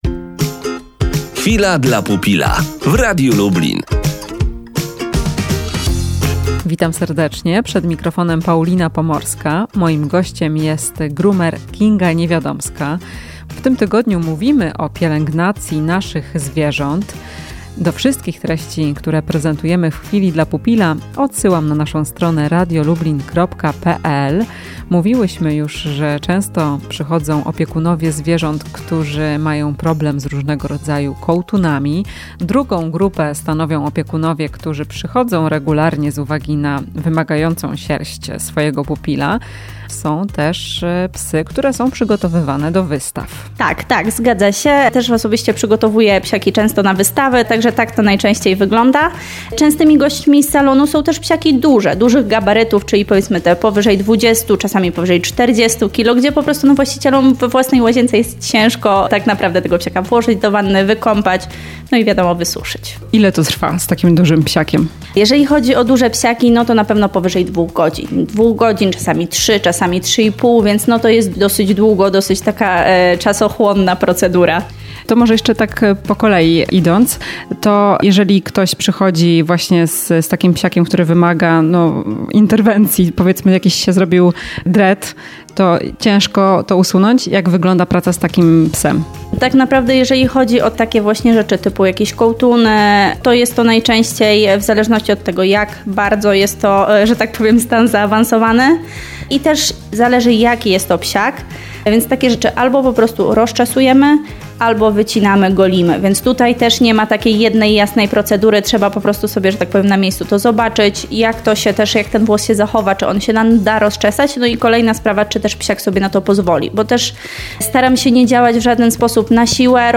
W „Chwili dla pupila" wyjaśniamy, jakie psy wymagają wizyty u groomera i jak często warto do niego chodzić. Rozmowa z